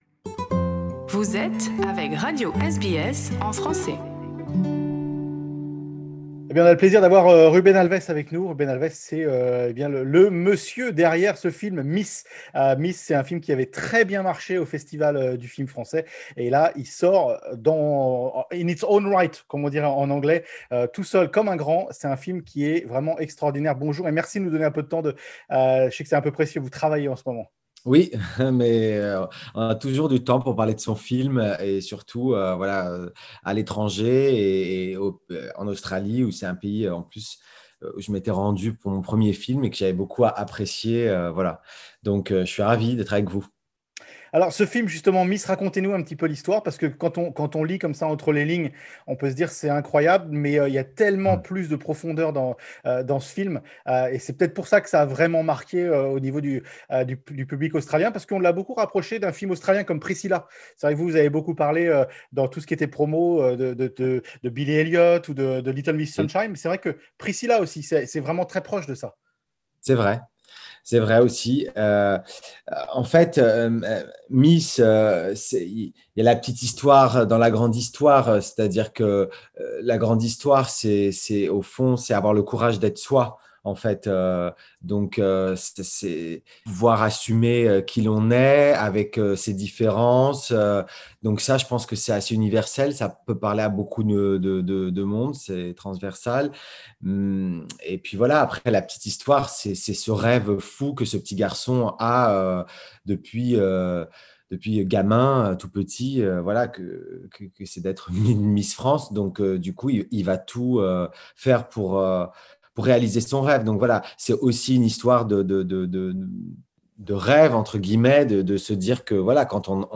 Rencontre avec Ruben Alves, le réalisateur du film "Miss" qui ouvre, après un succès au Festival du film Français de l'Alliance Française, dans les cinémas à travers l'Australie.